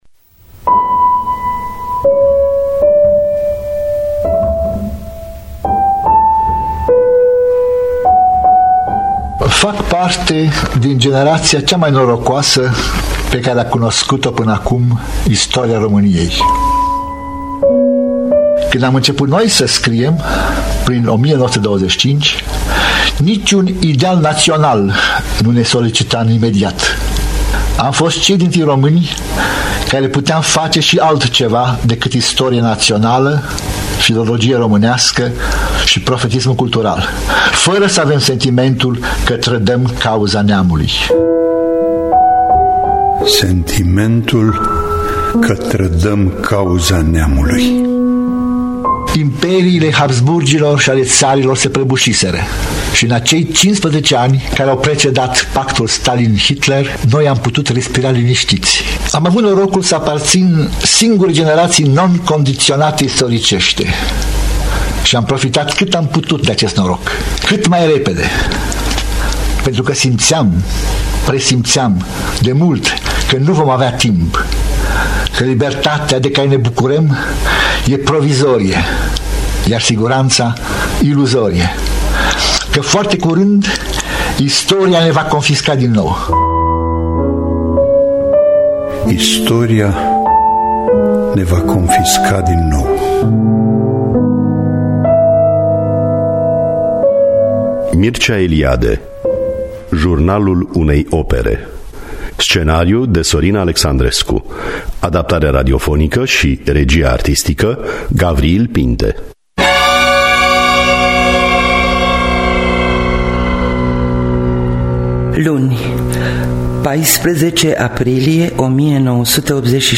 Scenariu radiofonic de Sorin Alexandrescu.